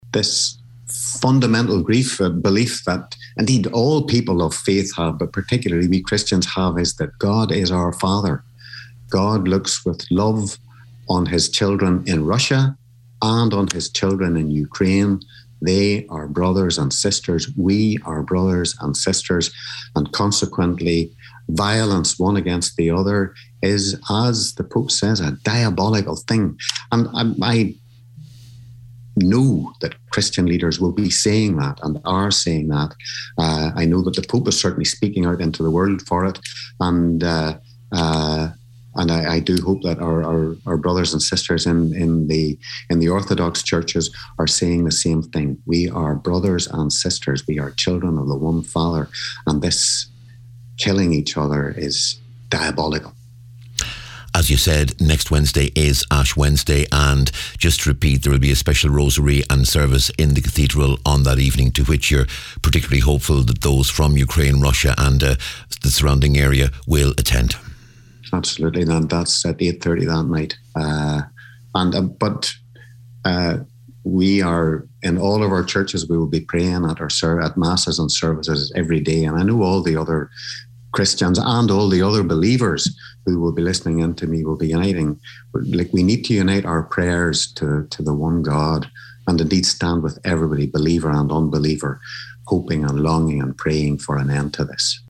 Bishop of Raphoe & Chair of the Bishop Council for Justice & Peace says Alan McGuckian everyone needs to unite in prayer for end to this: